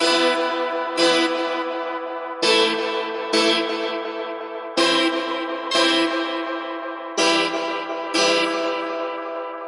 现场育科键04 100BPM
描述：直播遥控录音
声道立体声